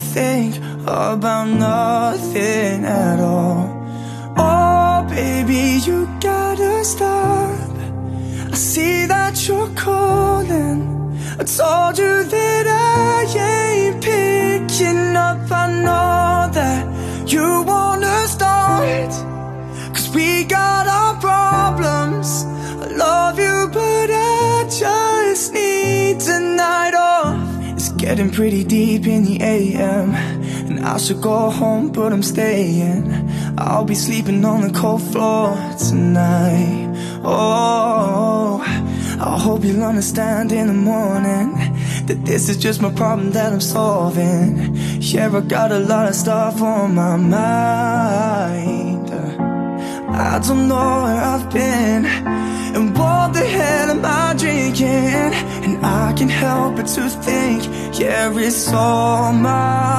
BREAKFUNK